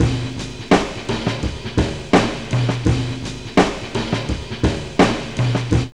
JAZZLP6 84.wav